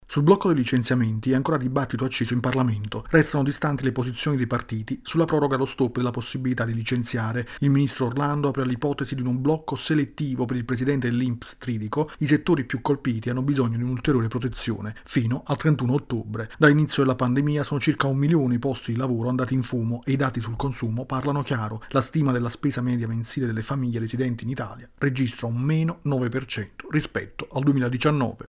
La questione del blocco dei licenziamenti accende il dibattito e non c’è ancora una soluzione. Il servizio